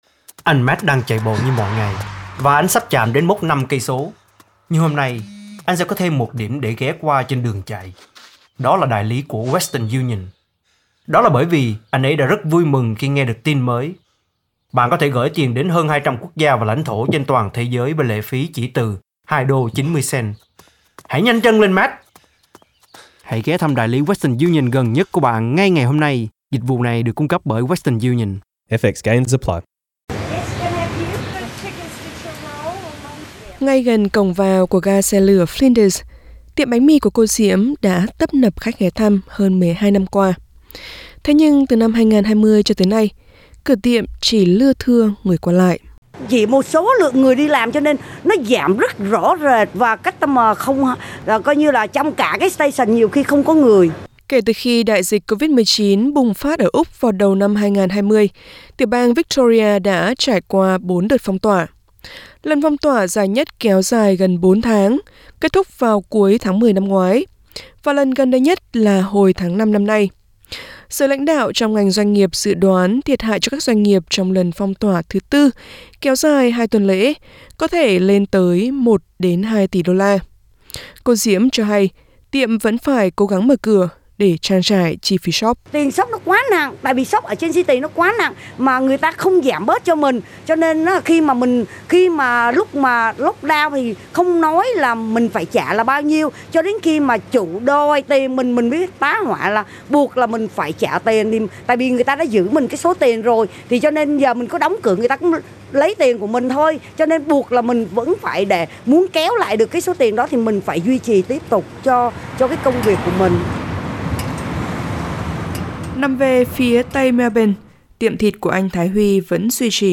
Mời quý vị bấm vào phần audio để nghe toàn bộ bài tường thuật.